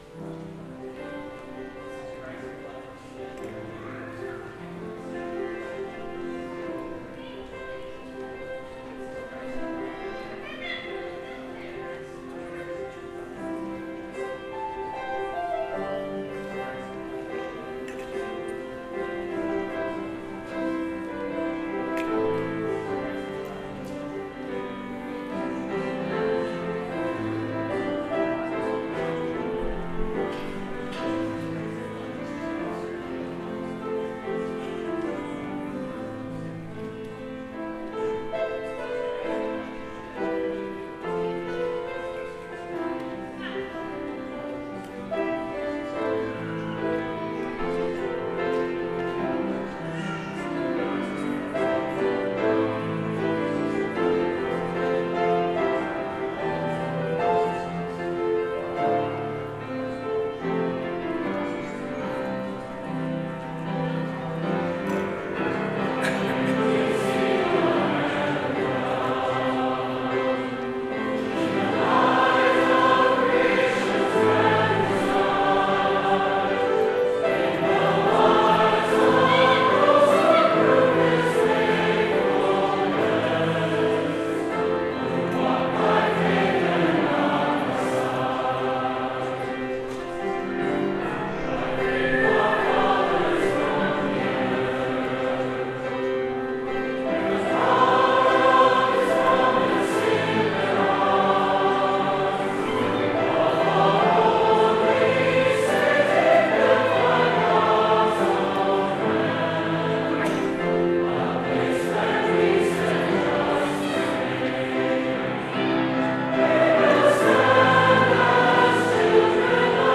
Organist
Concordia University A Cappella Choir